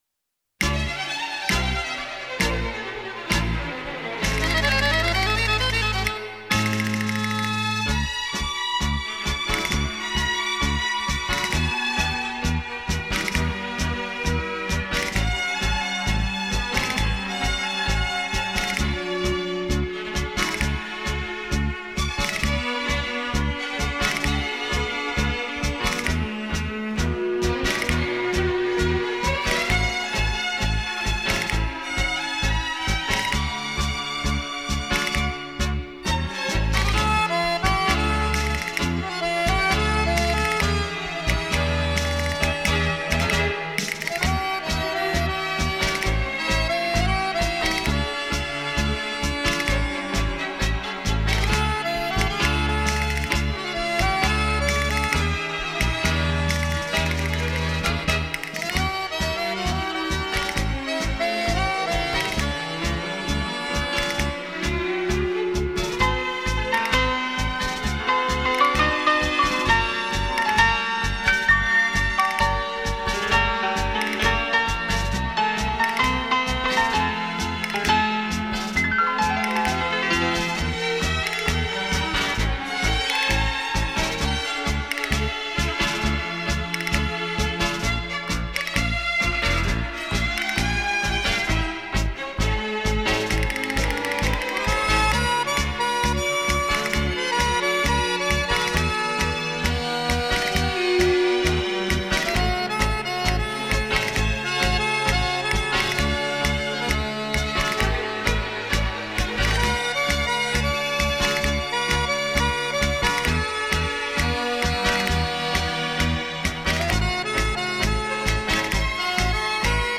CD圣经上榜发烧天碟，弦乐靓绝，层层叠叠，如丝般幼滑，空气感无敌，乐器质感强烈，定位精准，堂音丰满，场面浩瀚。
Tango